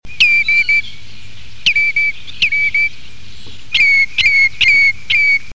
Chevalier gambette
tringa totanus